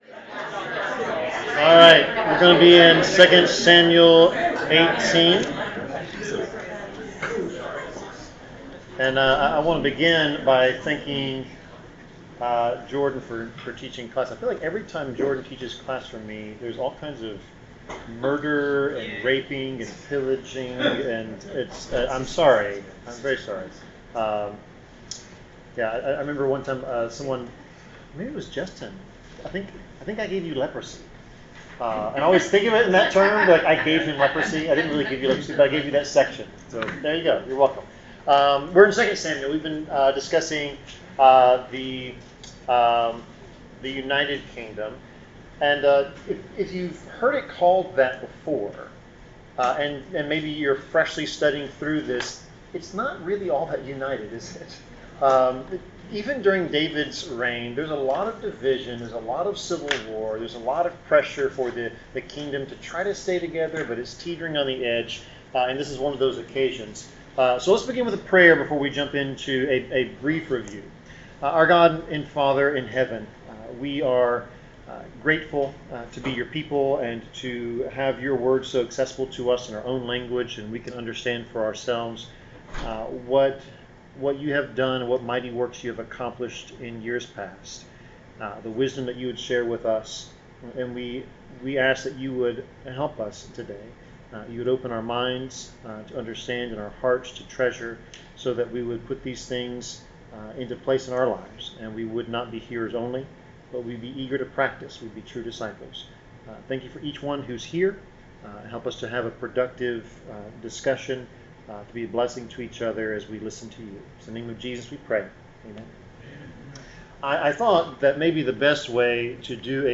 Bible class: 2 Samuel 18
Service Type: Bible Class